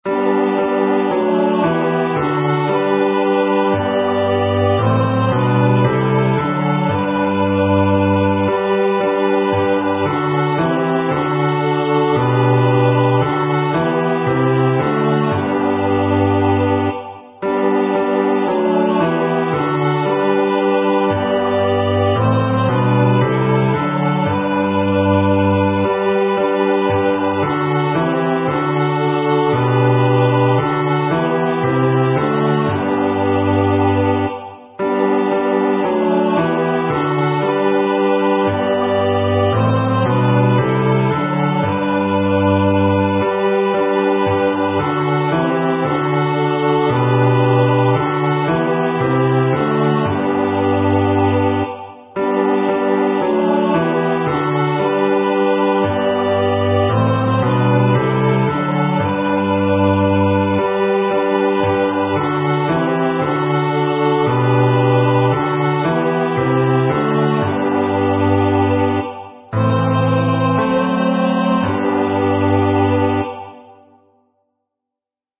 Genre: SacredMotet